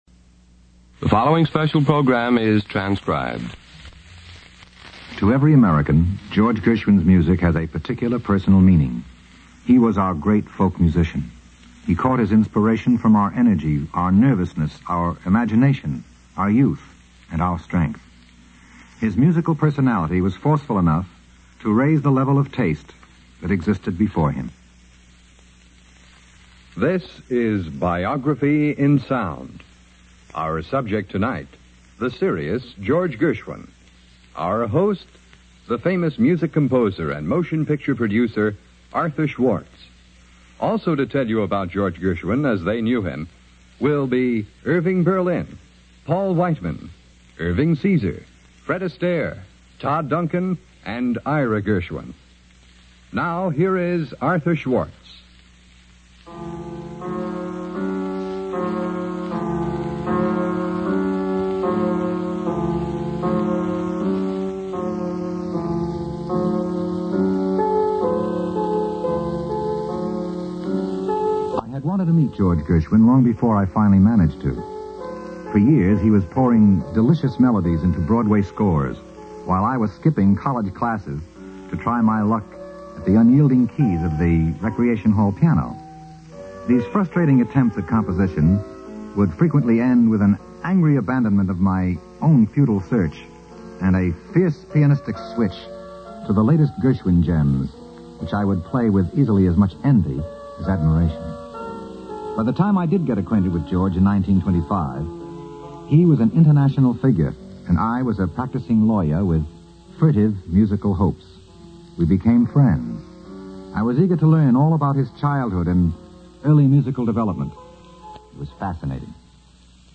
Featuring Arthur Schwartz narrator with Morton Gould Orchestra, Irving Caesar, Max Dreyfus, Todd Duncan.